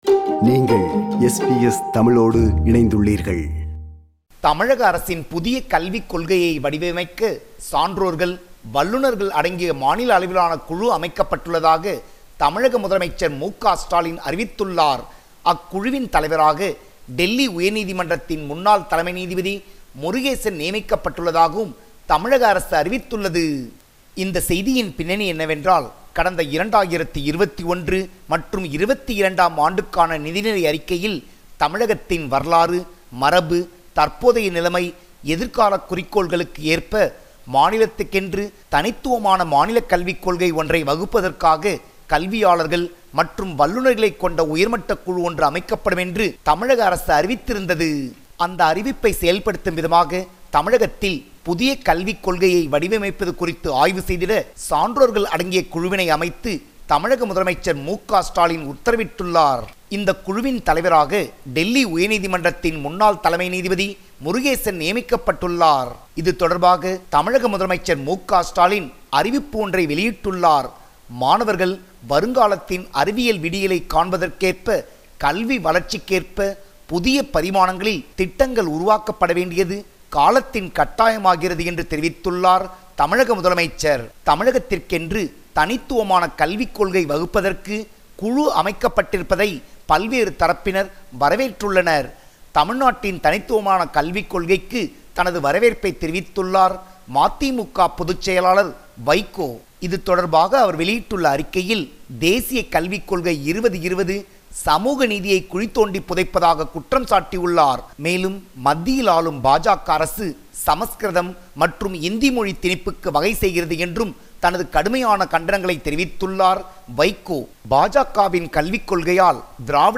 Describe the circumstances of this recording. Listen to SBS Tamil at 8pm on Mondays, Wednesdays, Fridays and Sundays on SBS Radio 2.